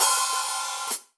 Index of /musicradar/Hi Hats/Sabian B8
CYCdh_Sab_OpHat-04.wav